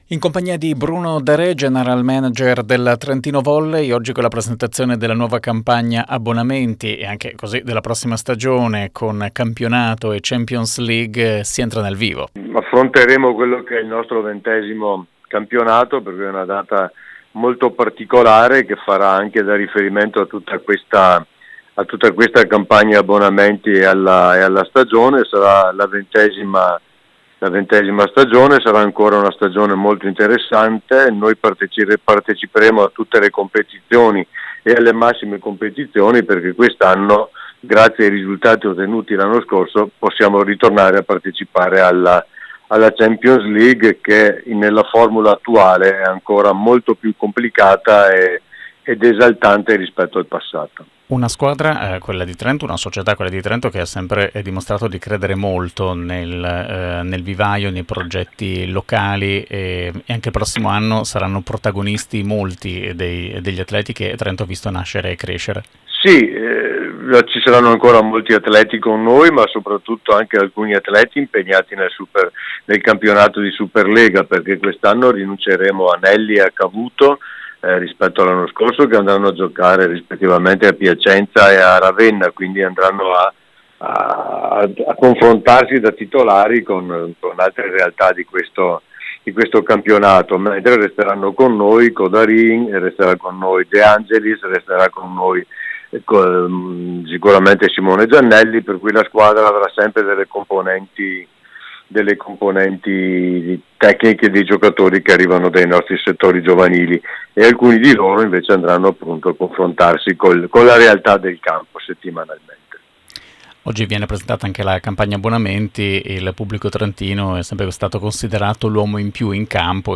intervistato da Radio Dolomiti sulla Campagna Abbonamenti